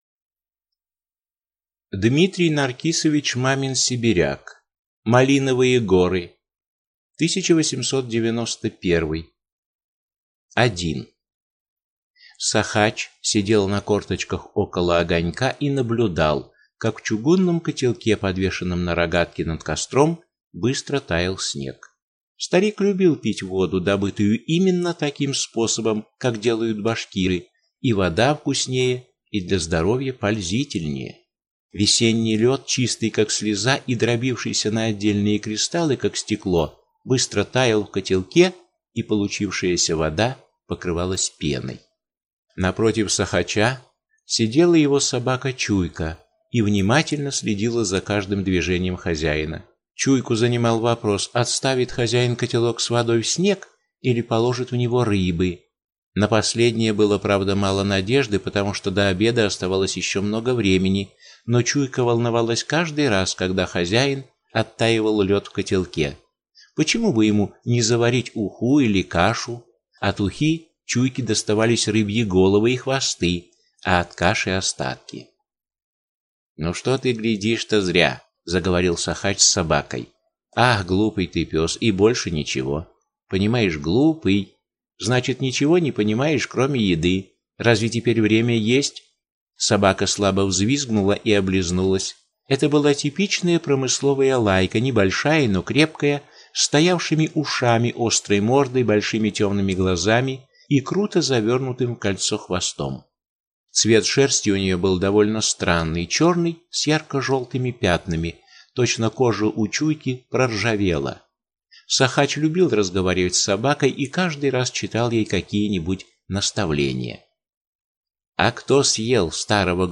Аудиокнига Малиновые горы | Библиотека аудиокниг